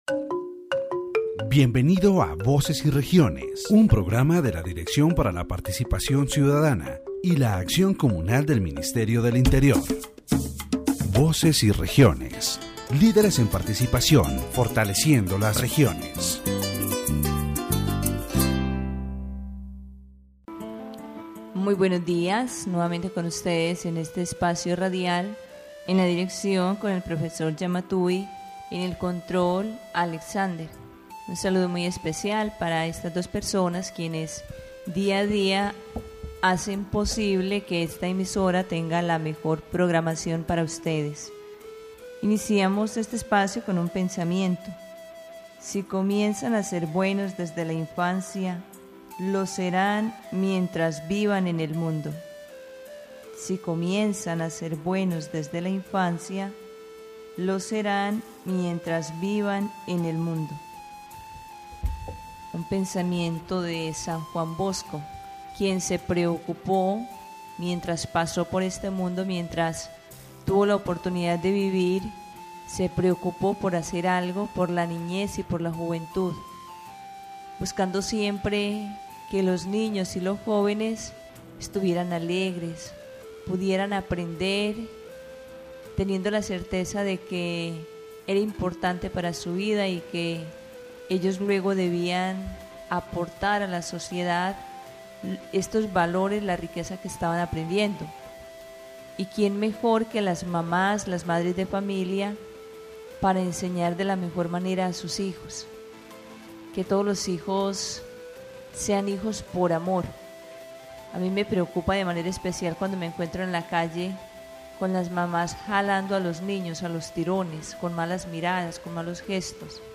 In a radio program, the host reflects on the importance of family and spirituality in raising children. She highlights how parental treatment influences children's emotional and moral well-being, promoting values of peace and hope.